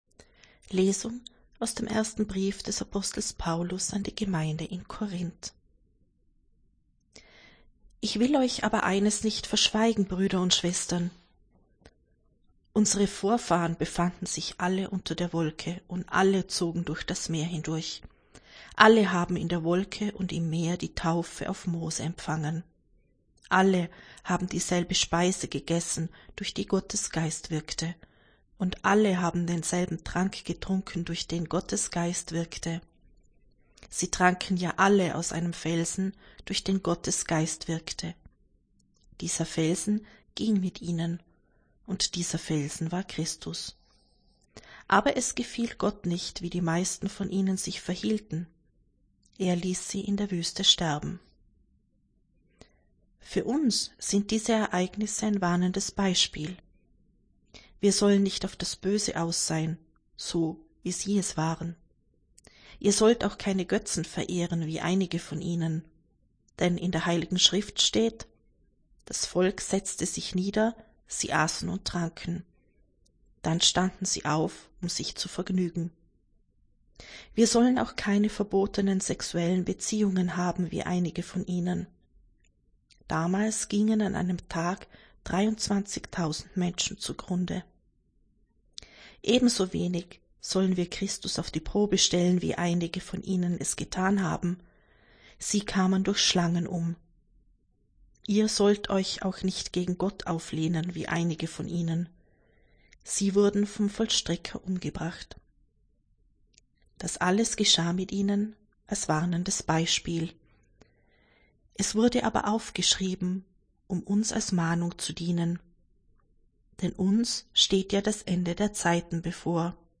Wenn Sie den Text der 2. Lesung aus dem ersten Brief des Apostels Paulus an die Gemeinde in Korínth anhören möchten:
Wir wollen einen Versuch starten und werden ab dem Beginn des neuen Lesejahres die Texte in der Länge der biblischen Verfasser lesen.